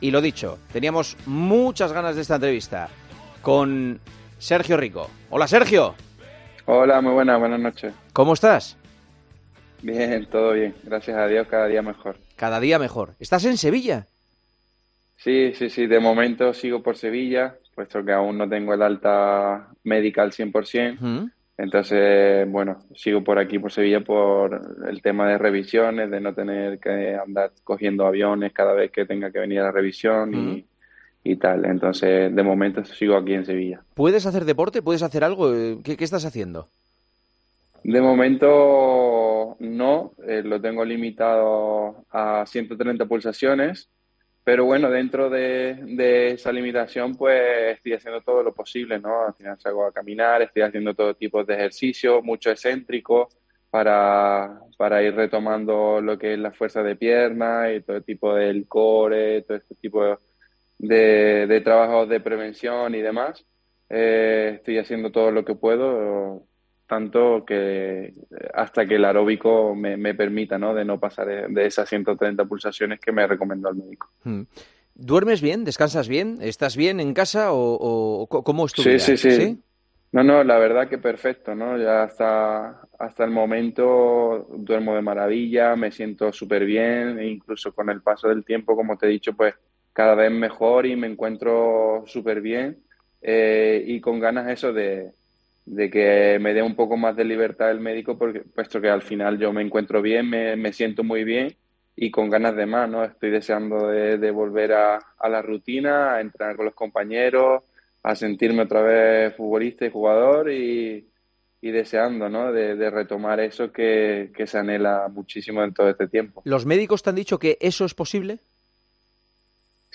Este jueves, Sergio Rico atendió la videollamada de El Partidazo de COPE, con Juanma Castaño, para contarnos cómo está siendo su recuperación, para contagiarnos de su buen estado de ánimo y para revivir el milagro que le ha tocado vivir.
Rico atendió a la Cadena COPE desde Sevilla, donde está cerca de su familia y donde todavía tiene que pasar varias revisiones e intenta recuperar su buen tono físico hasta que los médicos le puedan dar el alta definitiva.